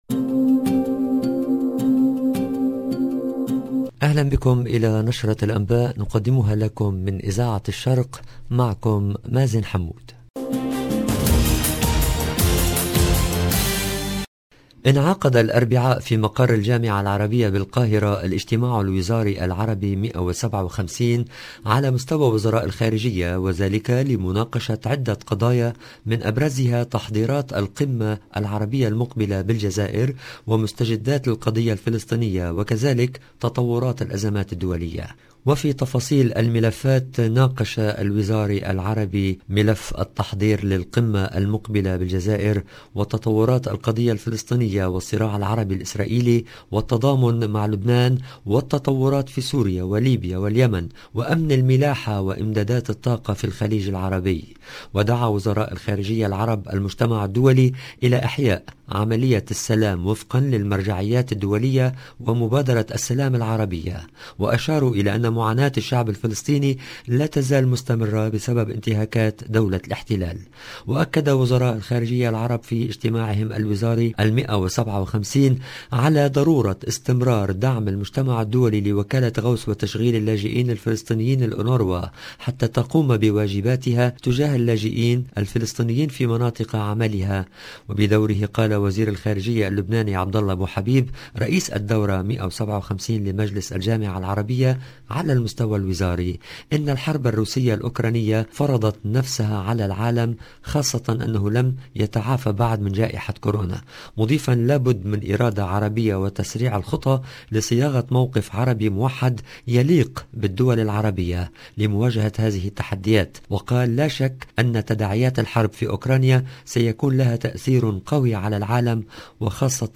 LE JOURNAL DU SOIR EN LANGUE ARABE DU 9/03/22